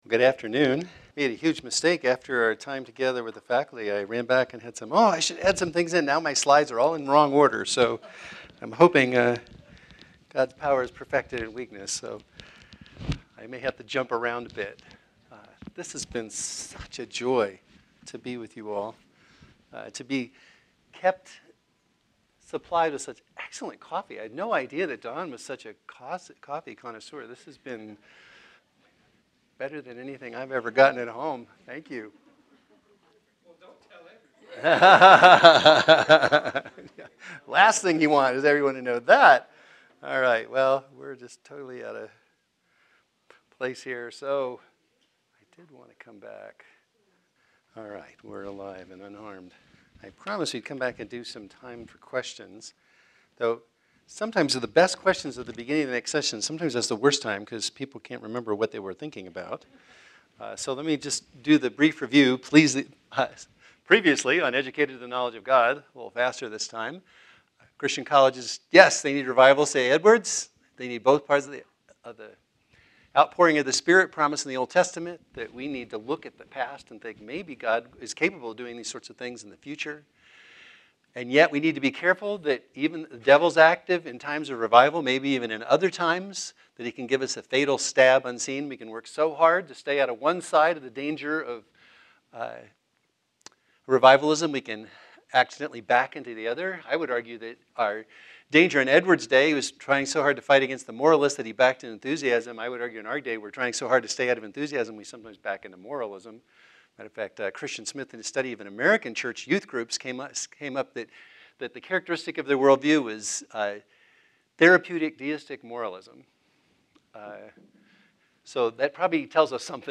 Series: Dr. Garry Owens M.D. Memorial Academic Lectures | Educated to the Knowledge of Christ